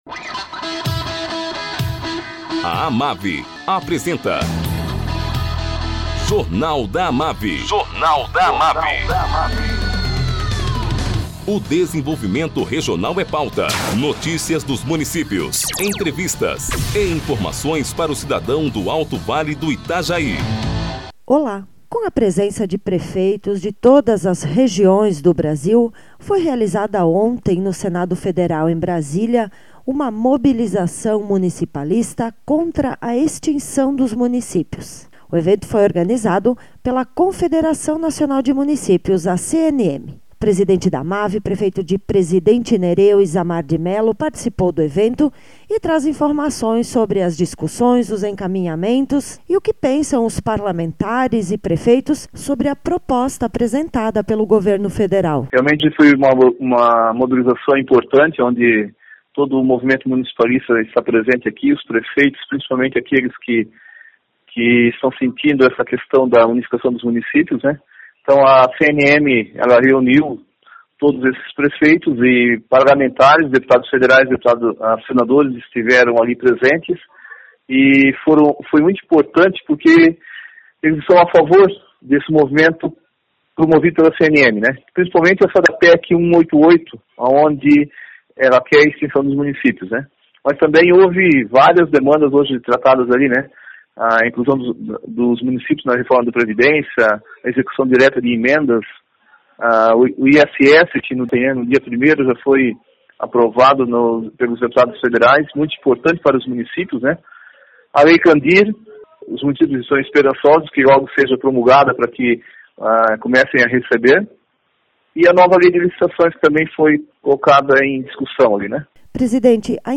Presidente da AMAVI, prefeito Isamar de Melo, fala sobre a mobilização municipalista realizada ontem, em Brasília, contra a extinção de Municípios.